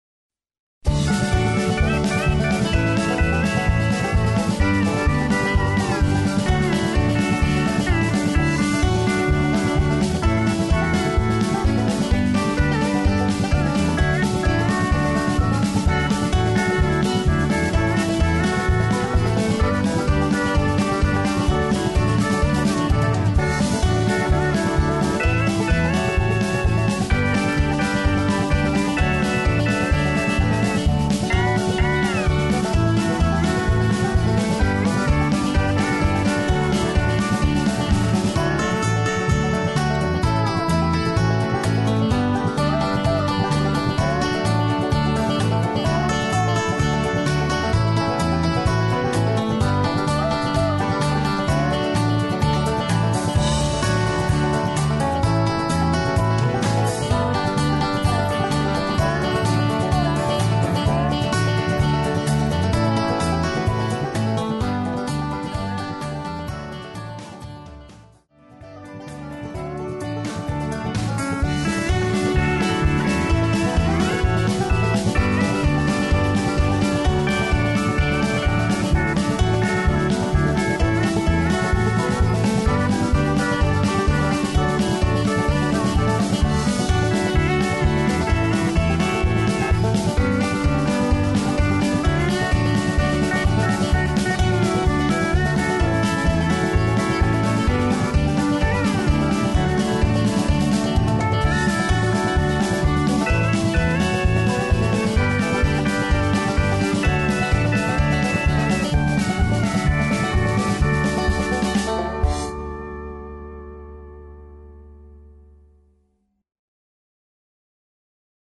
Instrumental TRacks